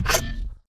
Select Robot 4.wav